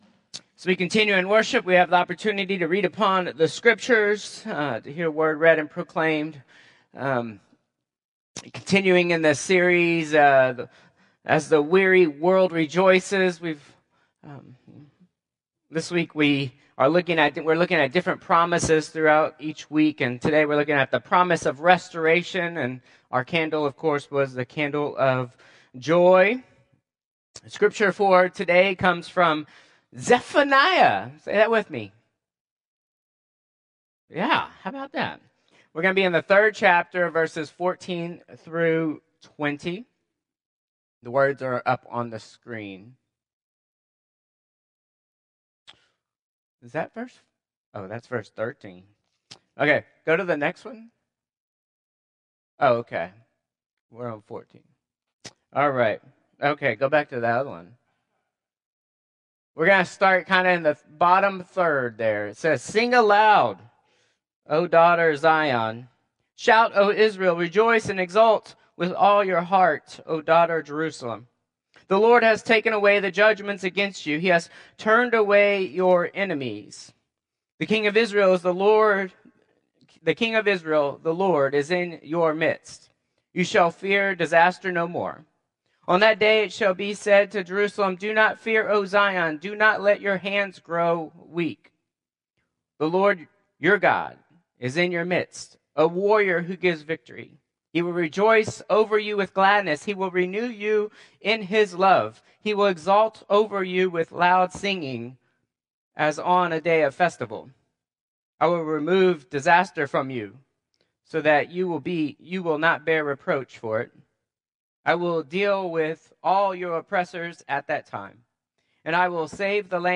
Contemporary Service 12/15/2024